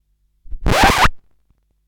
Record Scratch #1
comedy crackle effect lp needle noise phonograph record sound effect free sound royalty free Funny